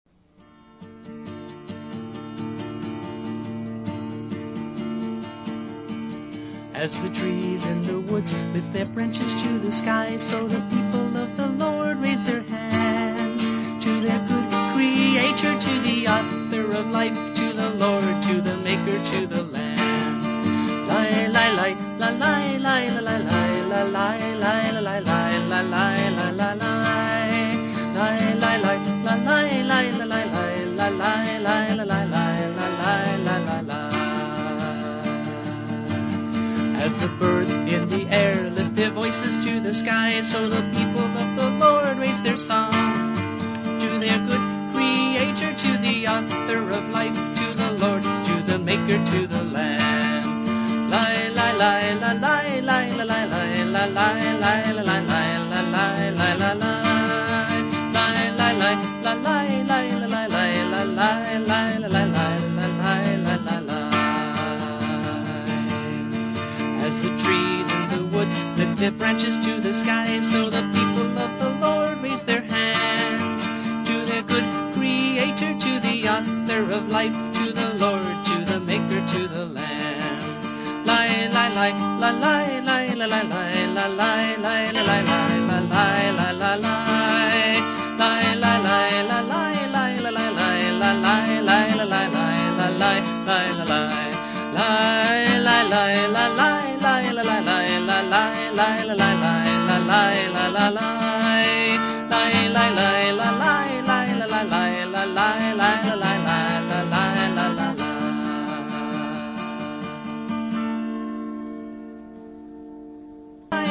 a lively Messianic chorus